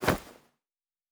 Fantasy Interface Sounds
Bag 05.wav